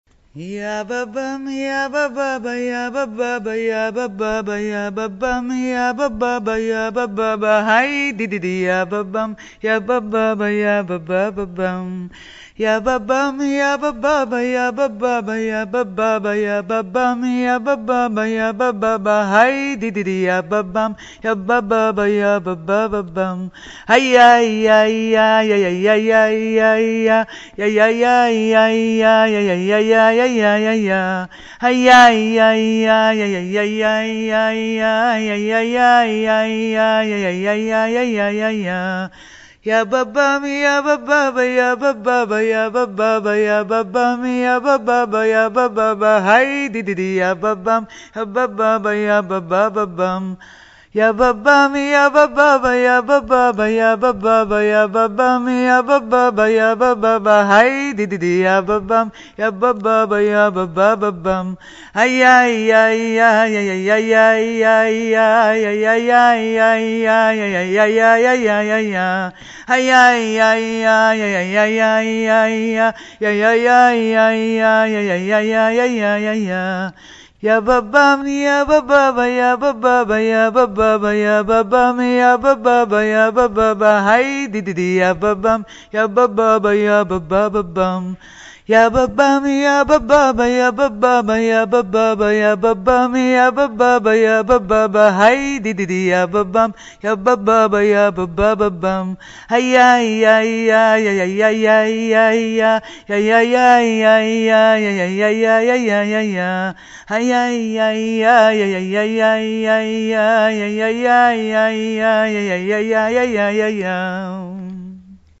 What a pleasure to hear these songs in a woman's voice!